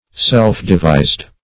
Self-devised \Self`-de*vised"\, a.